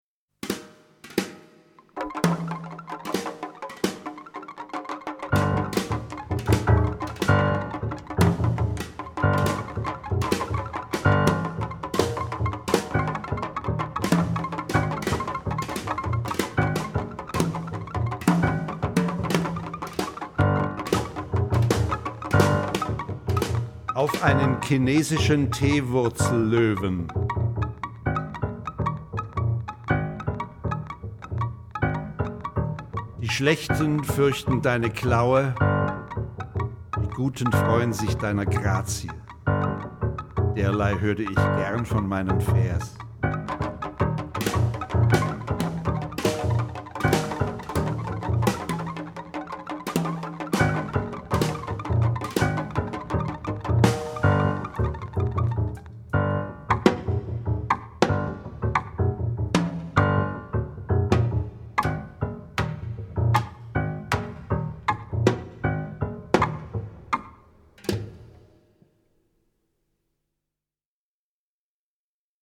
sassofoni
pianoforte
contrabbasso